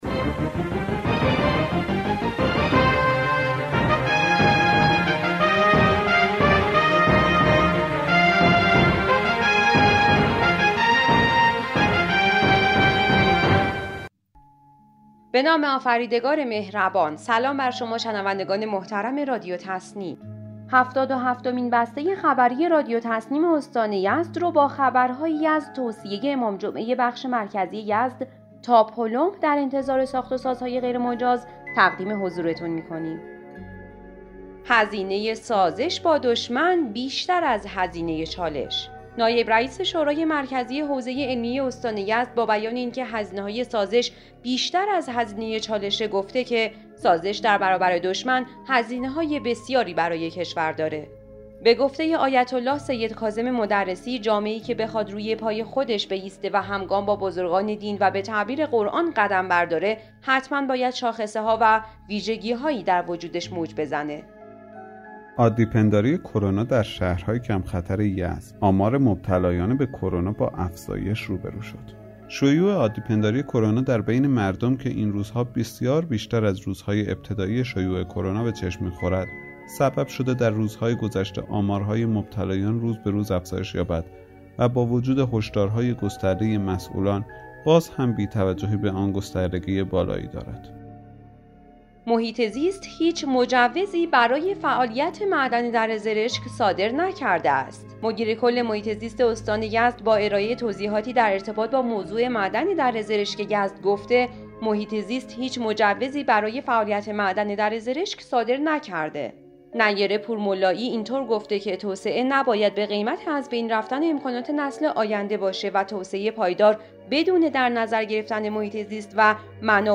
به گزارش خبرگزاری تسنیم از یزد, هفتاد و هفتمین بسته خبری رادیو تسنیم استان یزد با خبرهایی از هشدارهای امام جمعه بخش مرکزی یزد در مورد هزینه‌های سازش با دشمن, راه‌اندازی سامانه ترخیص دادگستری استان یزد، توضیح مدیرکل محیط‌زیست استان در مورد معدن دره زرشک, شیوع عادی‌پنداری کرونا در یزد و نظارت نانوایی‌های یزد توسط کار گروه آرد و نان منتشر شد.